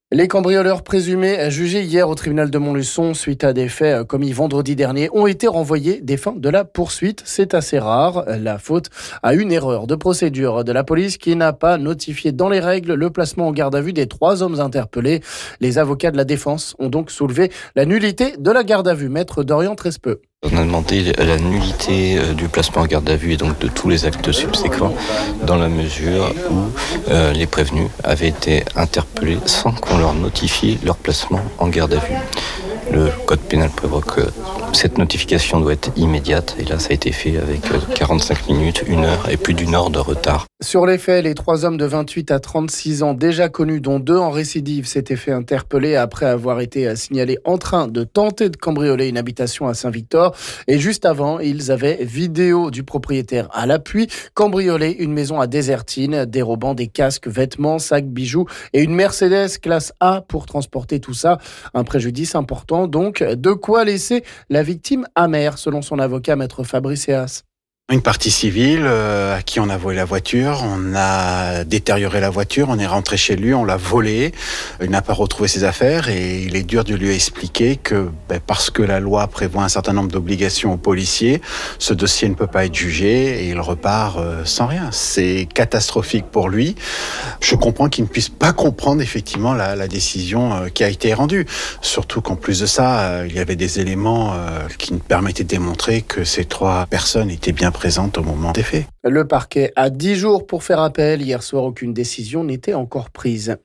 Explications et réactions des avocats des deux parties ici...